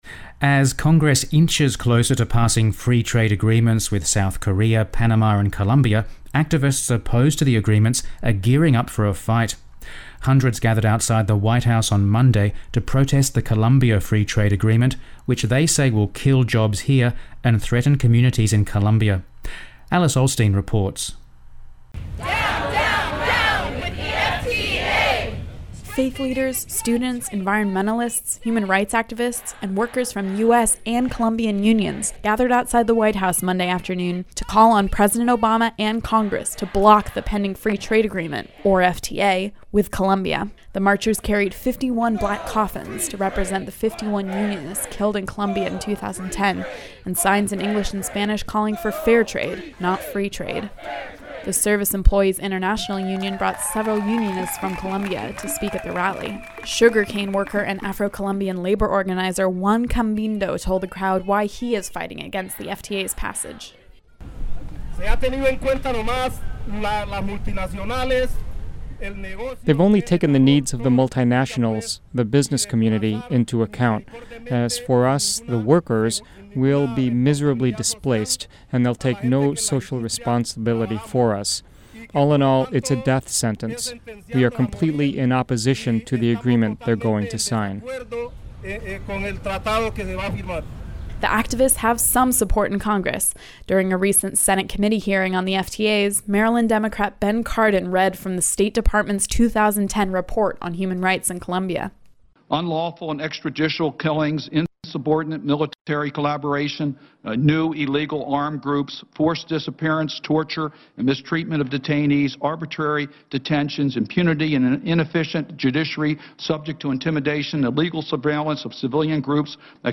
Hundreds gather outside the White House to protest the Colombia free trade agreement
As Congress inches closer to passing free trade agreements with South Korea, Panama and Colombia, activists opposed to the agreements are gearing up for a fight. Hundreds gathered outside the White House on Monday to protest the Colombia free trade agreement, which they say will kill jobs here, and threaten communities in Colombia.